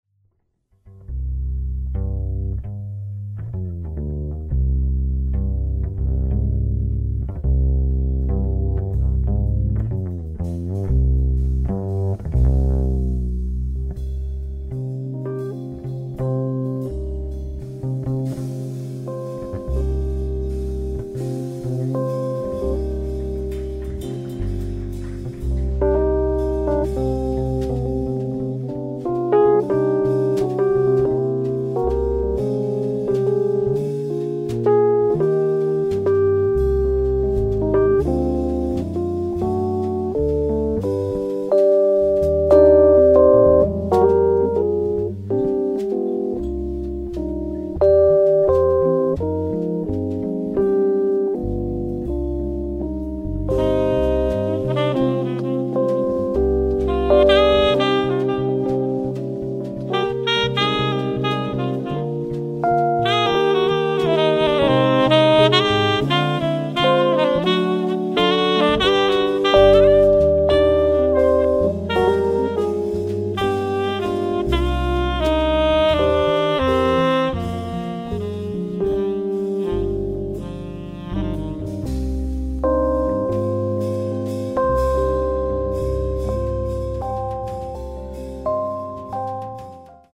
Catalogue -> Jazz & almost -> Collections, Jams, Live
keyboards, Fender Rhodes piano
alto & tenor saxes
guitar
acoustic bass
drums